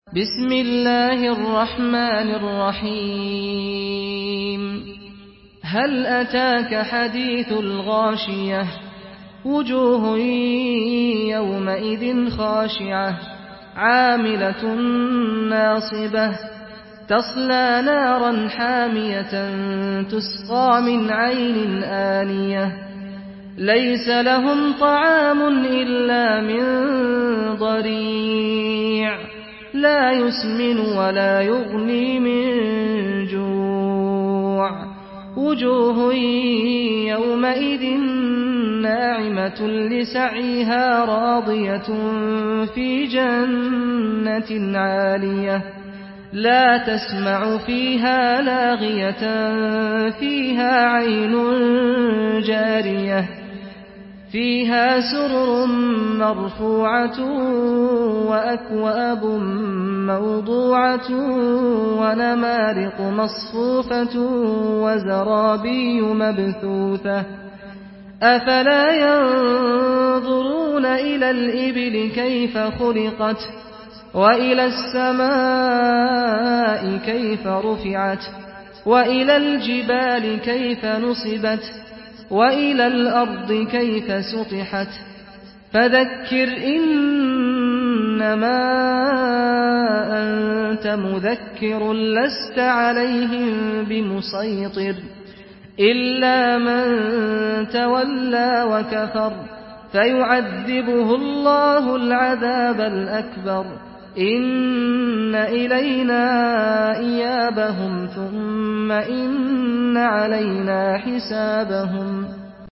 سورة الغاشية MP3 بصوت سعد الغامدي برواية حفص
مرتل حفص عن عاصم